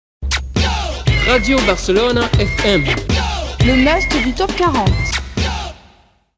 Indicatiu de l'emissora i del programa en francès.
FM